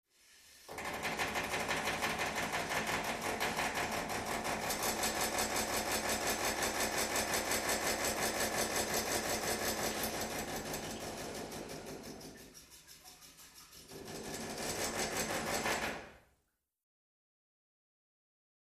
Pipes, Rattling W Air And Fluid Flow.